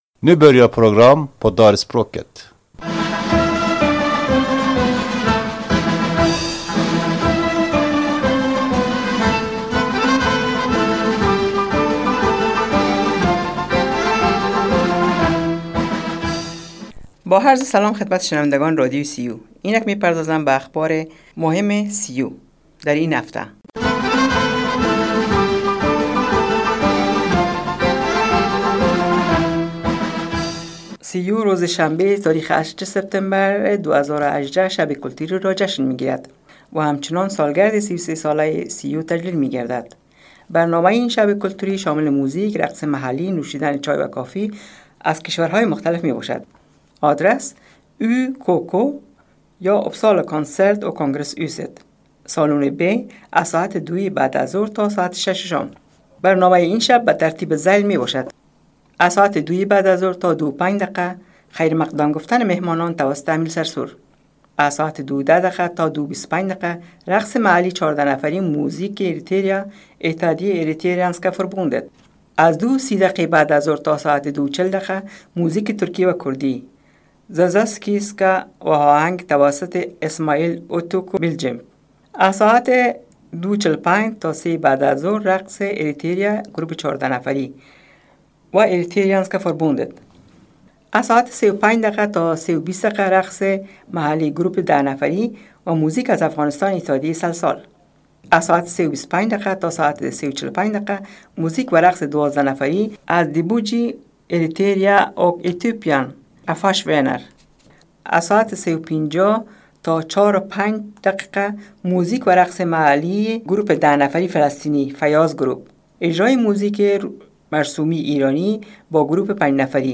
شنوندگان گرامی بخش دری رادیو سیو : این برنامه که هفته یکبار پخش میگردد شامل اخبار سیو ، اخبار محلی ، اخبار سرتاسری ، اخبار از افغانستان و موزیک میباشد